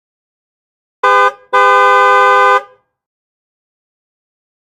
carHorn-3c4e1e54.mp3